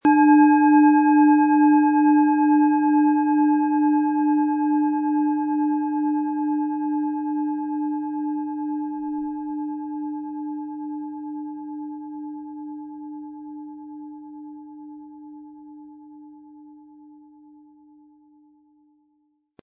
Planetenton 1
PlanetentonSaturn
SchalenformBihar
HerstellungIn Handarbeit getrieben
MaterialBronze